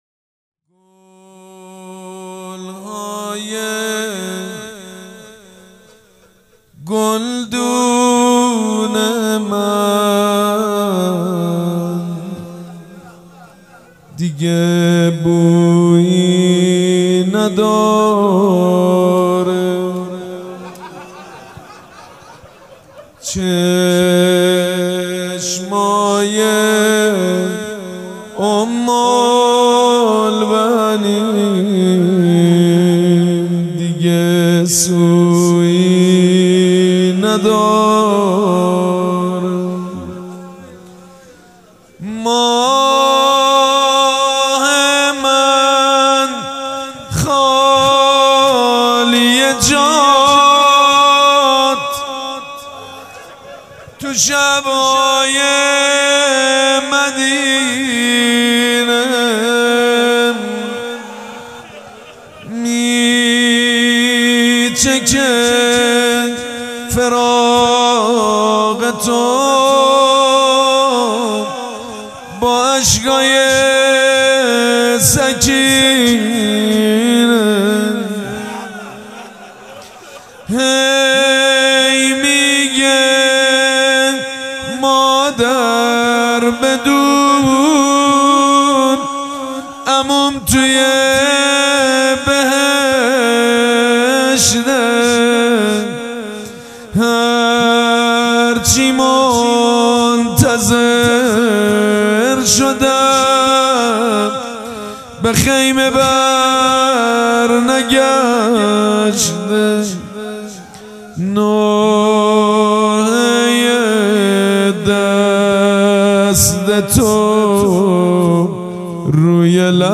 حاج سید مجید بنی فاطمه وفات حضرت ام البنین(س) 1396 هیئت ریحانه الحسین سلام الله علیها
سبک اثــر روضه مداح حاج سید مجید بنی فاطمه